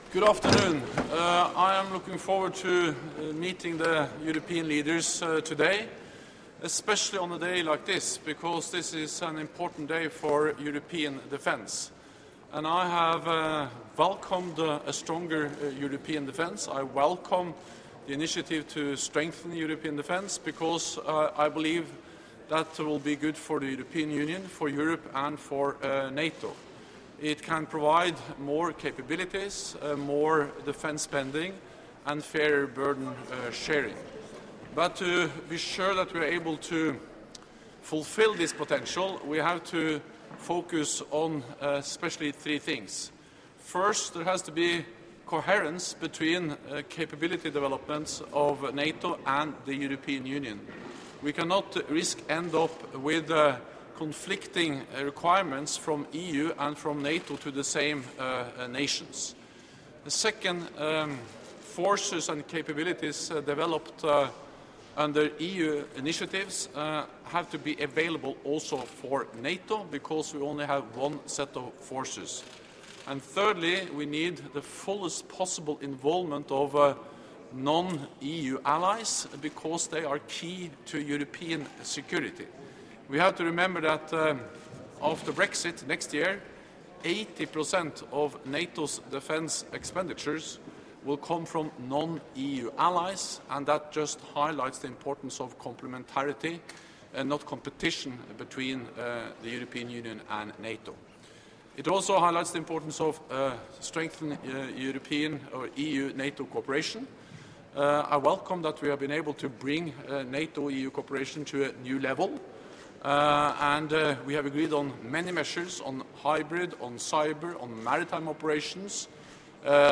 Doorstep statement
by NATO Secretary General Jens Stoltenberg at the start of the European Council meeting